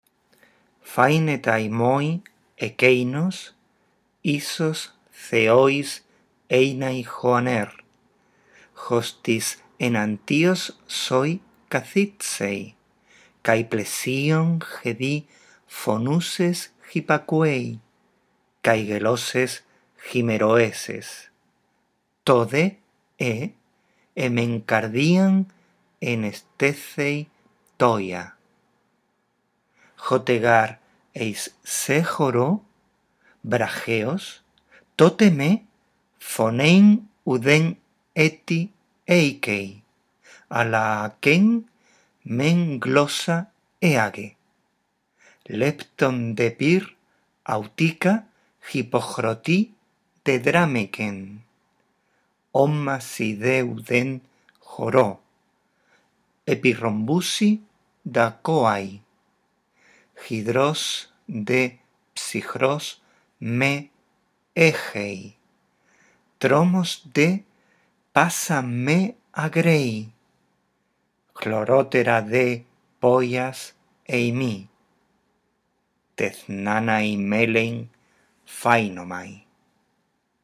Lee el poema 31 en voz alta, respetando los signos de puntuación. Después escucha estos tres archivos de audio y repite la lectura.